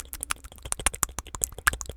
Animal_Impersonations
rabbit_eating_02.wav